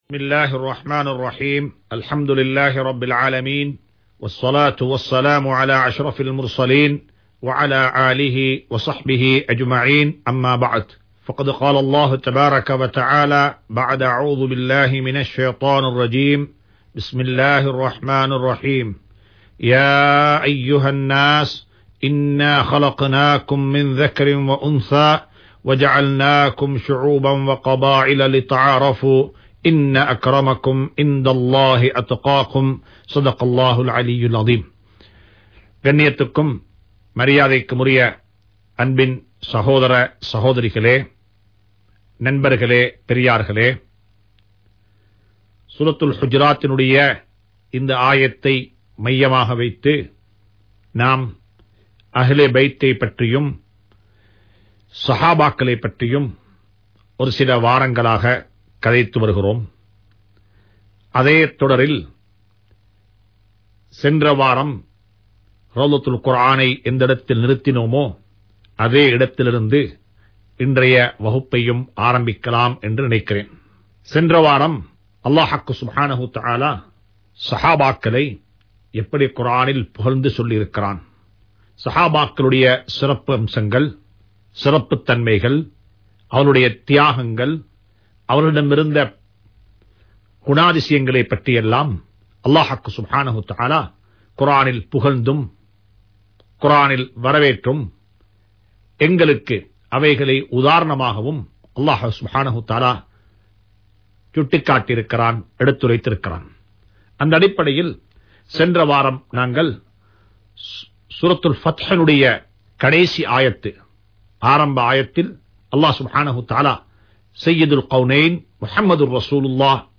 Thafseer 107(Sura Hujarat) | Audio Bayans | All Ceylon Muslim Youth Community | Addalaichenai